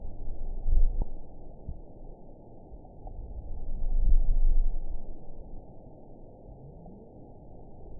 event 917866 date 04/19/23 time 11:11:50 GMT (2 years ago) score 9.46 location TSS-AB05 detected by nrw target species NRW annotations +NRW Spectrogram: Frequency (kHz) vs. Time (s) audio not available .wav